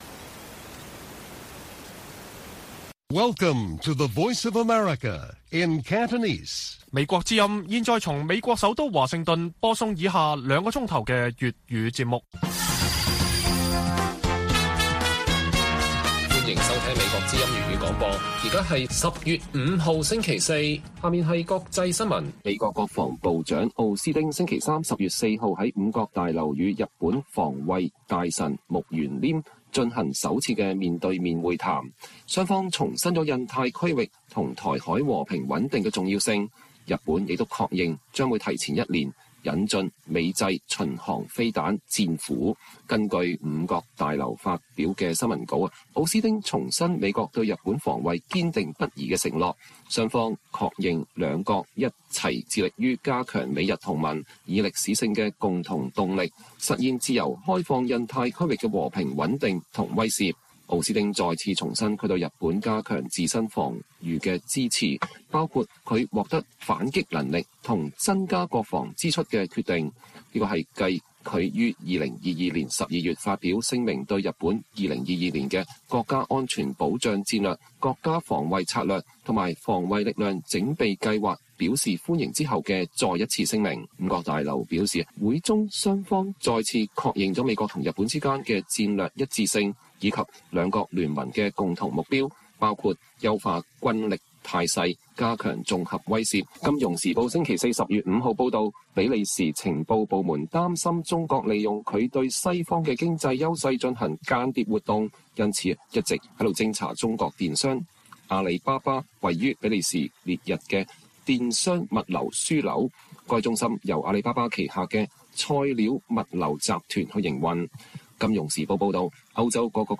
粵語新聞 晚上9-10點: 人權律師盧思位已被遣返中國 妻子感痛苦悲憤